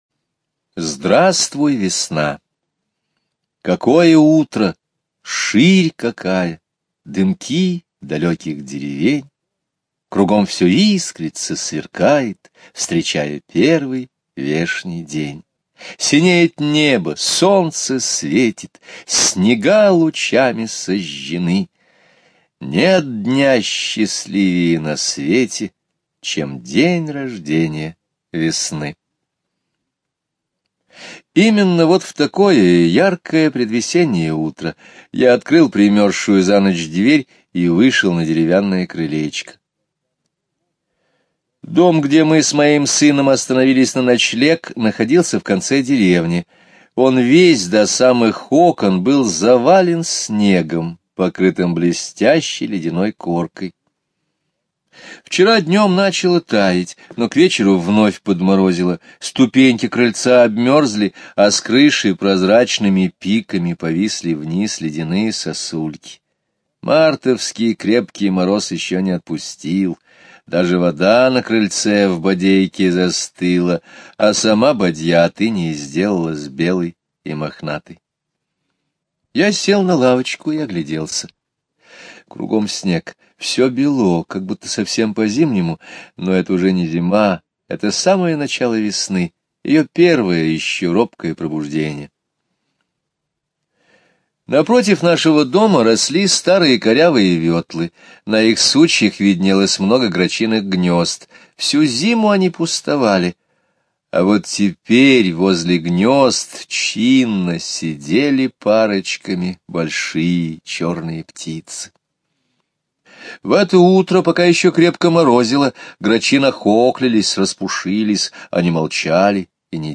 Слушайте Здравствуй, весна! - аудио рассказ Скребицкого Г. Рассказ о пробуждении природы ранней весной: прилетают грачи, пригревает солнце.